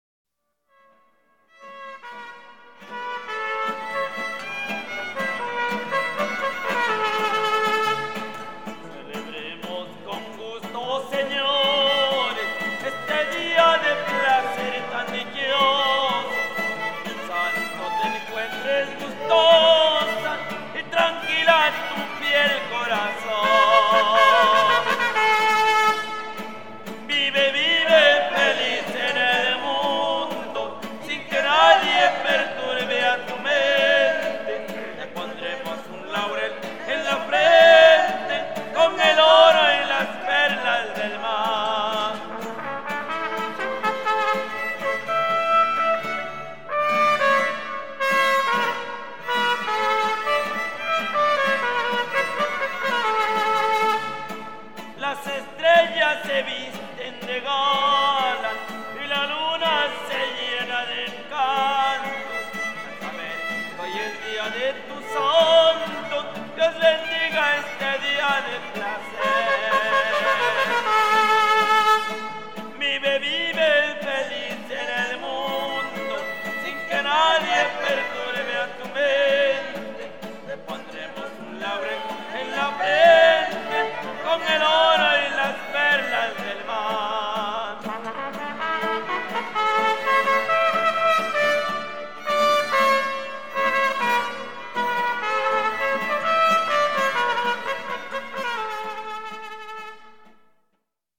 Fiesta de la candelaria